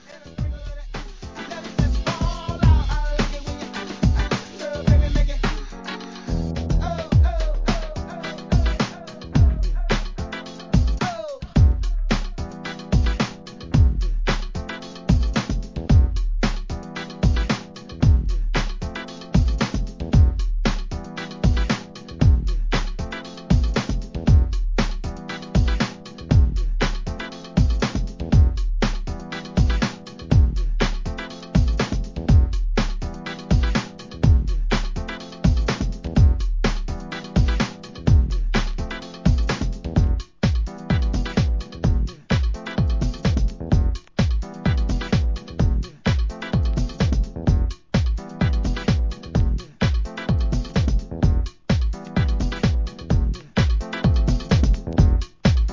HIP HOP/R&B
BPMが上下、最終的には四つ打ちへ!!!